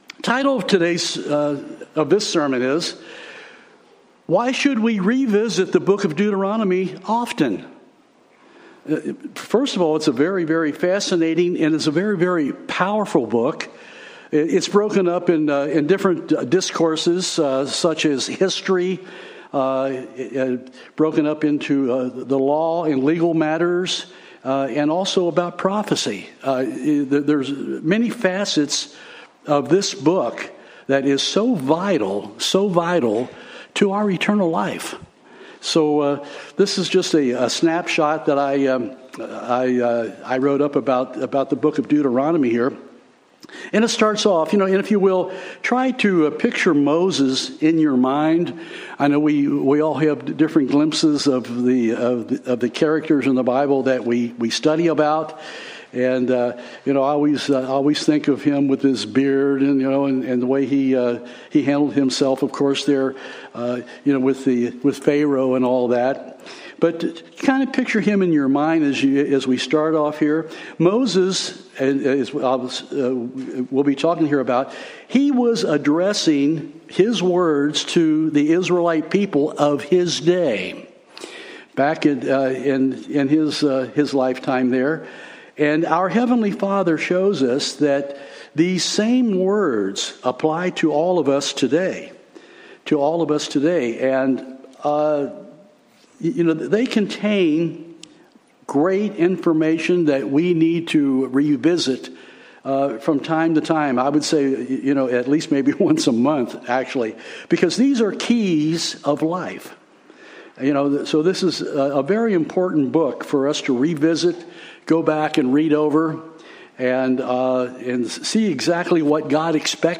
Sermon
Given in Nashville, TN